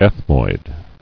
[eth·moid]